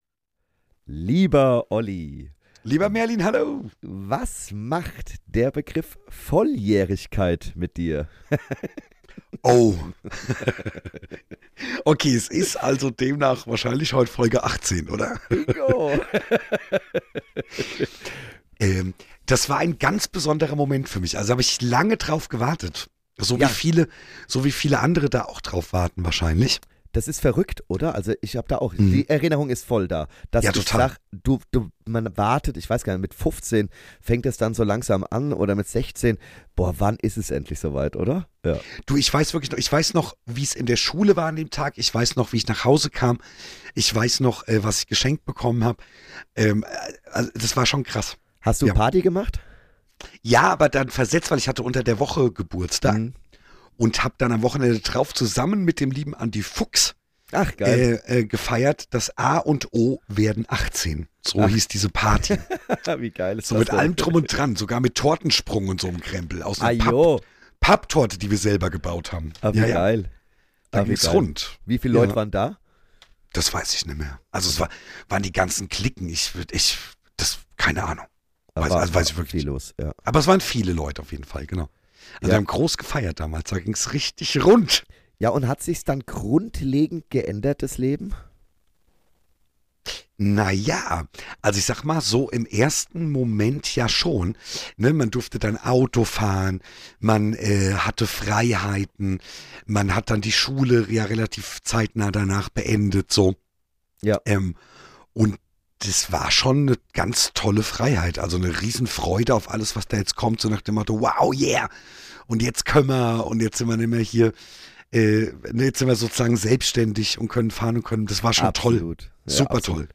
Wie immer ungeschnitten und ungefiltert - ein Gespräch der Heiterkeit :-) Schreibt uns und folgt uns auf Instagram Mehr